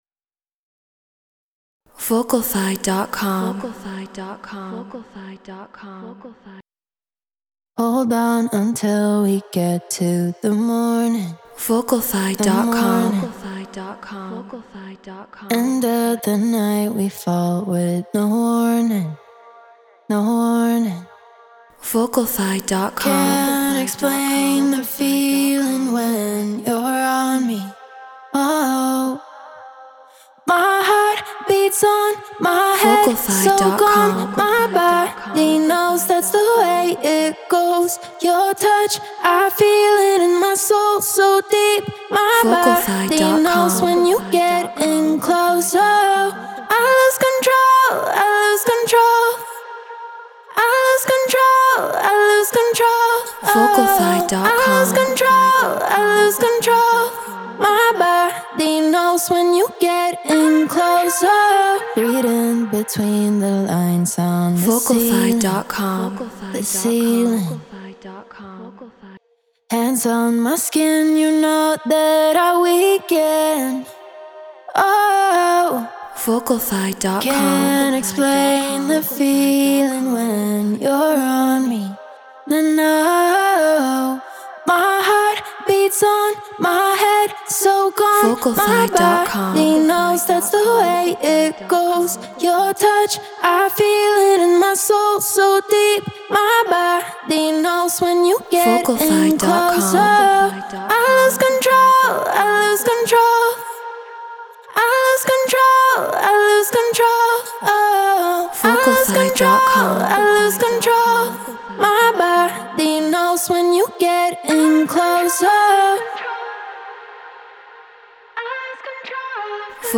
House 124 BPM Fmin